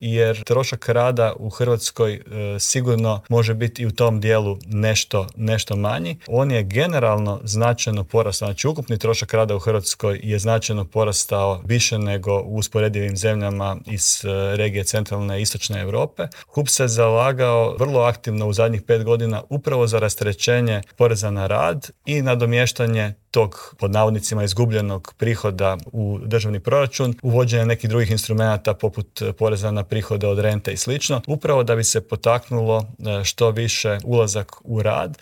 Intervjuu tjedna Media servisa